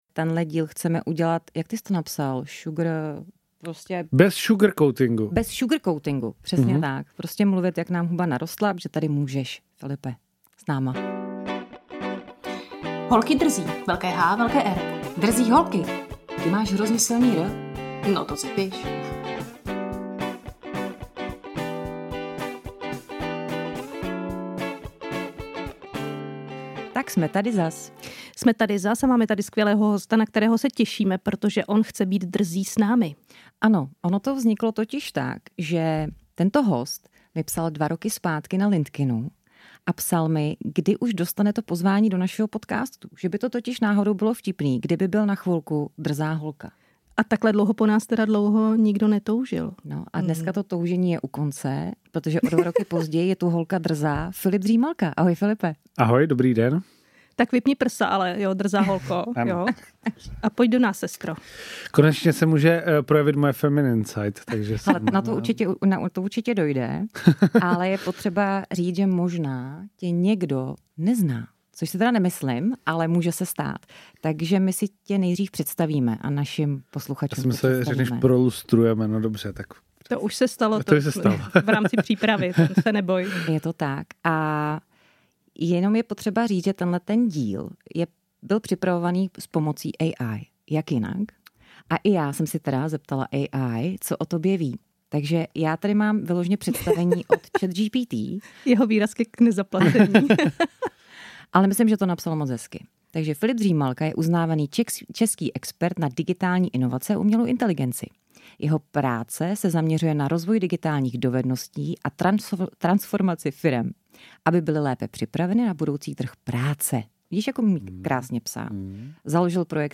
A to všechno samozřejmě v našem typicky drzém stylu, bez cenzury a bez přetvářky!